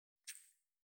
354塩を振る,調味料,カシャカシャ,サラサラ,パラパラ,ジャラジャラ,サッサッ,ザッザッ,シャッシャッ,シュッ,パッ,サッ,トントン,カラカラ,
効果音厨房/台所/レストラン/kitchen